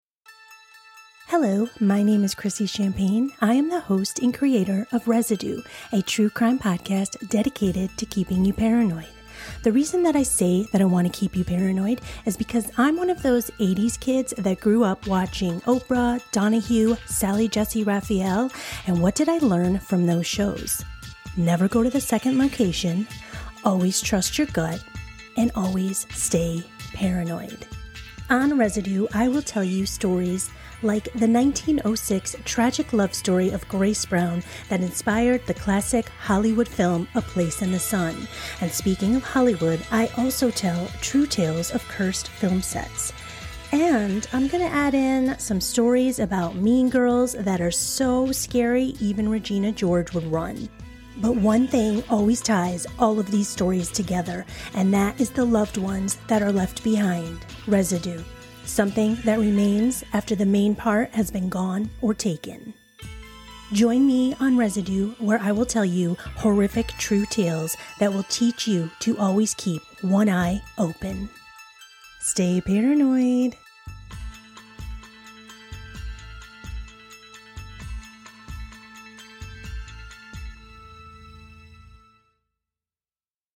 Trailer/Promo for Residue: A True Crime Podcast